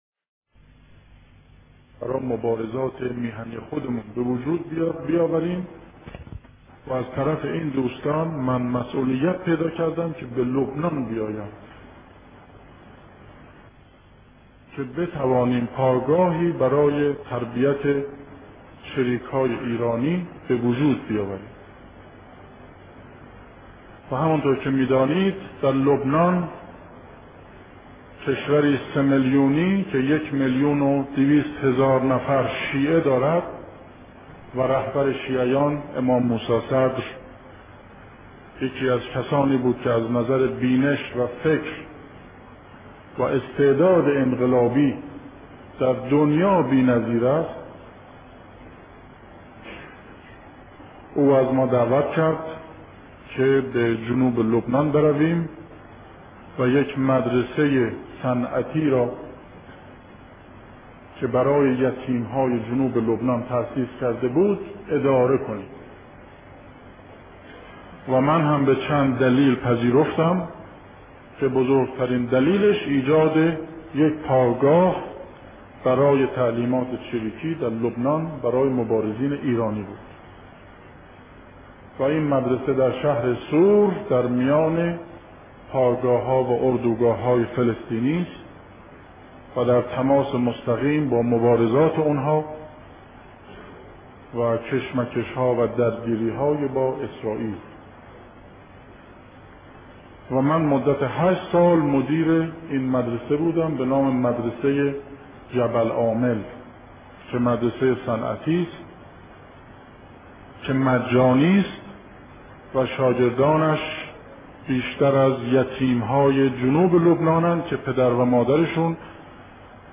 مجموعه‌ای از خاطرات شهید مصطفی چمران به روایت خود